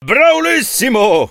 chuck_kill_vo_02.ogg